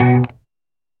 トゥワン
twang.mp3